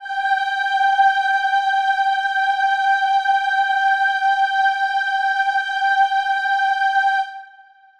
Choir Piano (Wav)
G5.wav